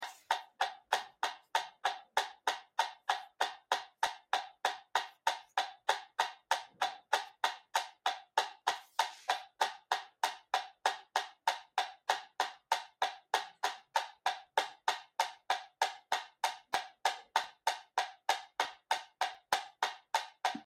tictac.mp3